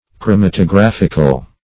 chromatographical \chro*mat`o*graph"ic*al\adj.
chromatographical.mp3